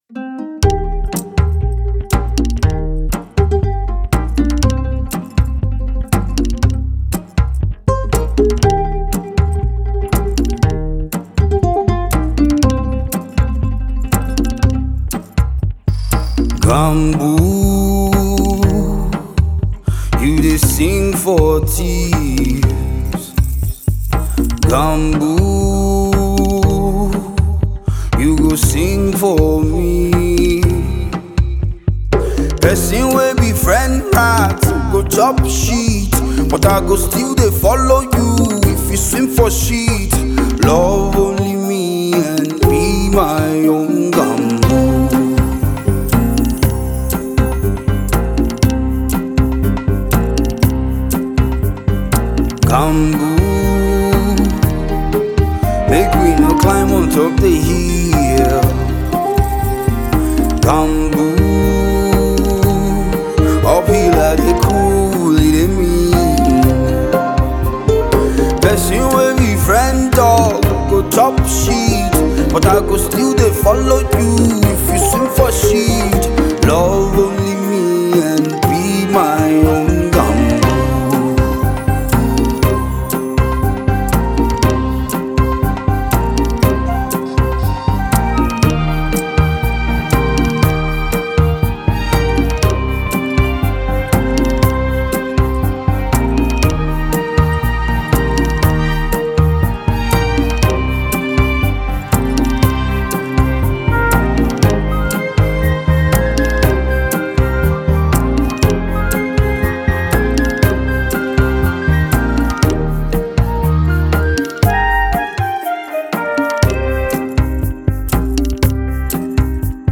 Nigerian Music